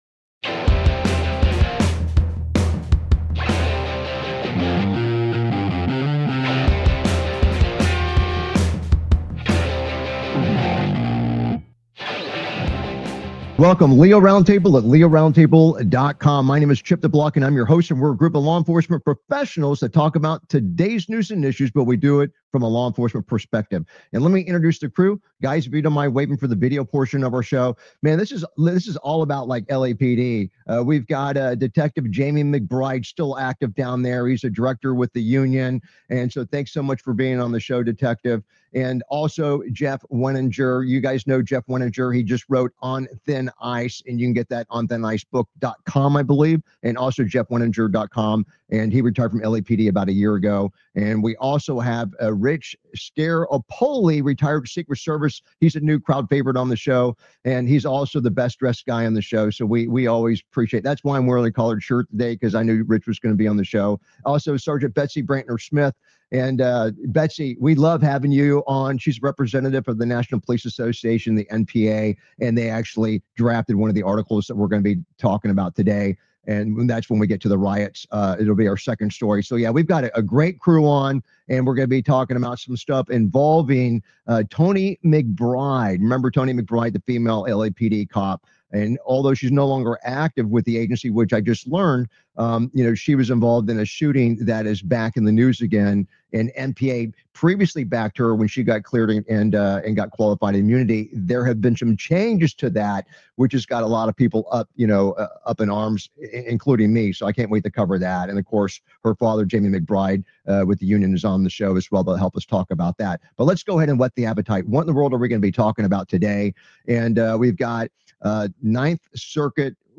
LEO Round Table is a nationally syndicated law enforcement satellite radio talk show discussing today's news and issues from a law enforcement perspective.
Their panelists are among a Who's Who of law enforcement professionals and attorneys from around the country.